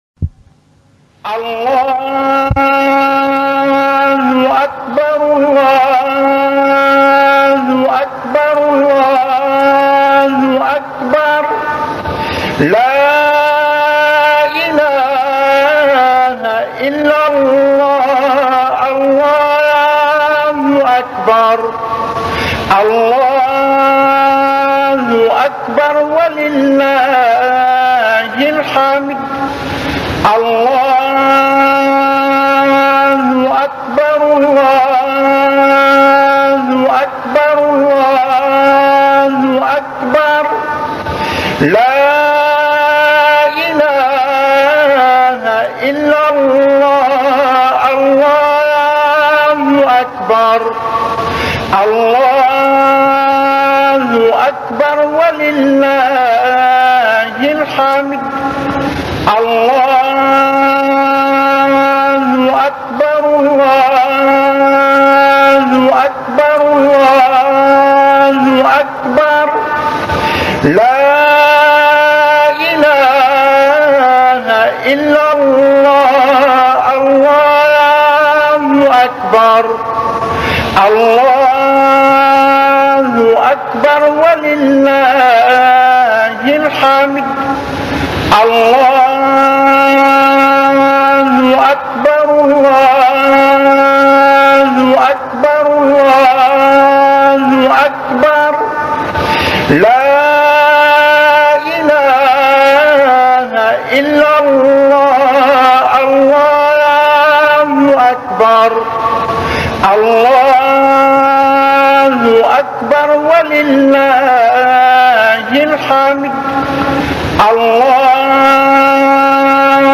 تكبيرات العيد